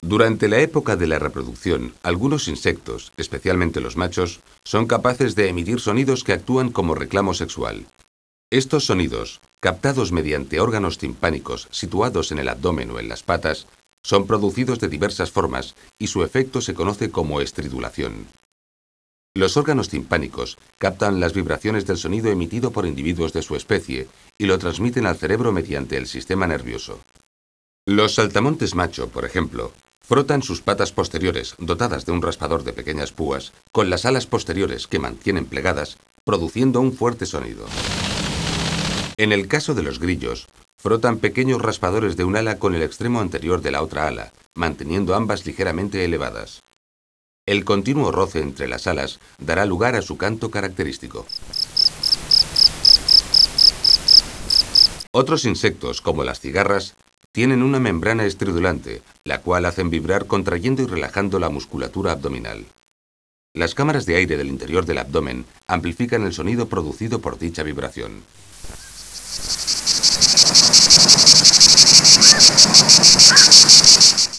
insectos.wav